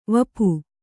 ♪ vapu